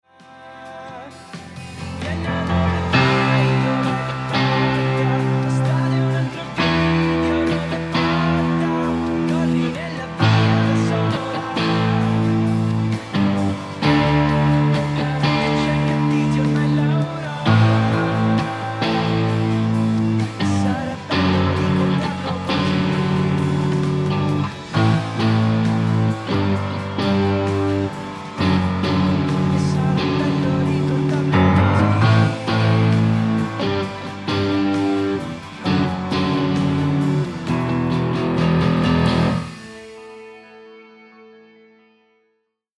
CHIT2_PIANO.mp3